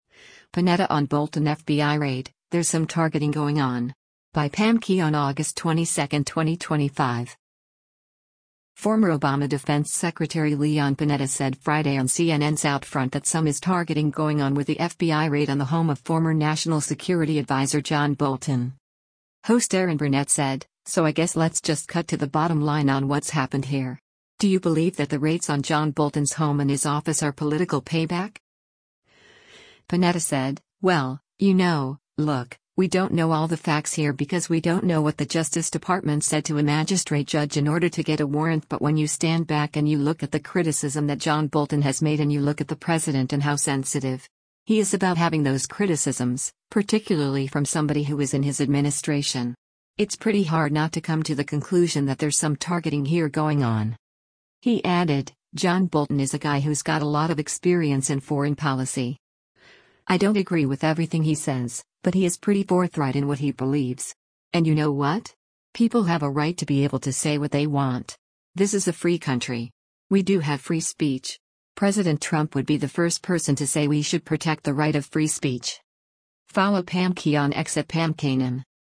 Former Obama Defense Secretary Leon Panetta said Friday on CNN’s “OutFront” that some is targeting “going on” with the FBI raid on the home of former National Security Advisor John Bolton.
Host Erin Burnett said, “So I guess let’s just cut to the bottom line on what’s happened here.